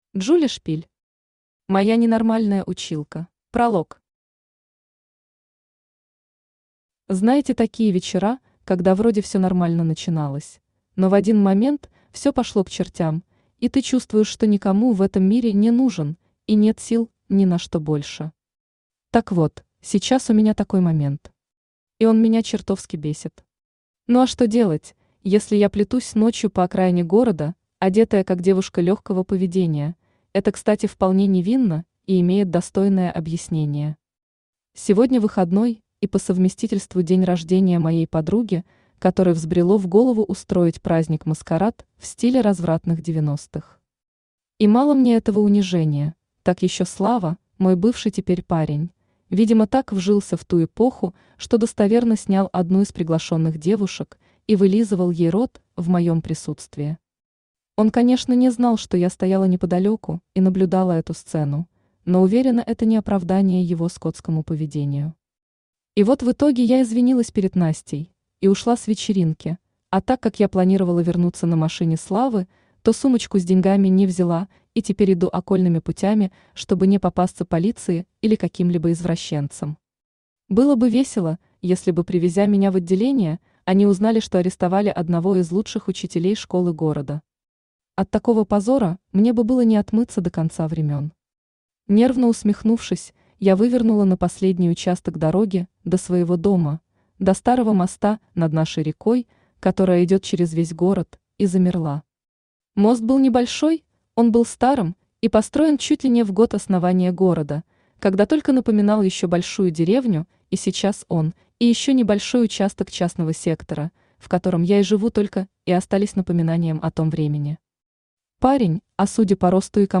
Аудиокнига Моя ненормальная училка | Библиотека аудиокниг
Aудиокнига Моя ненормальная училка Автор Джули Шпиль Читает аудиокнигу Авточтец ЛитРес.